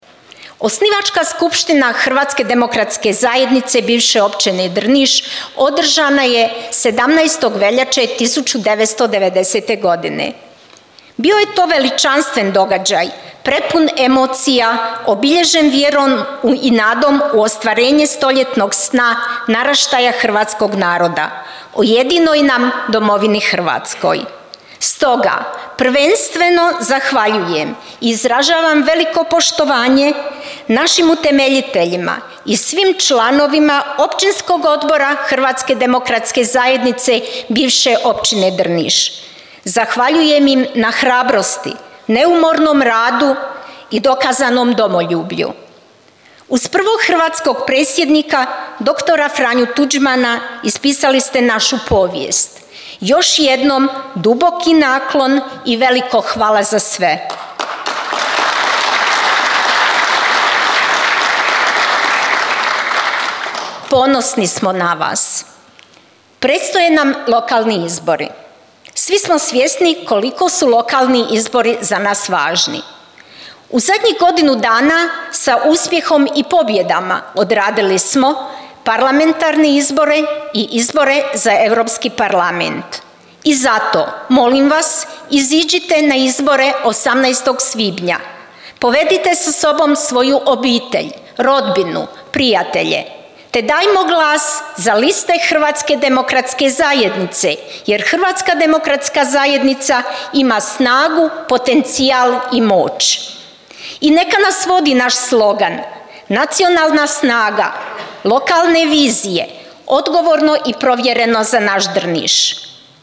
Drniški HDZ proslavio 35. obljetnicu osnutka te održao predizborni skup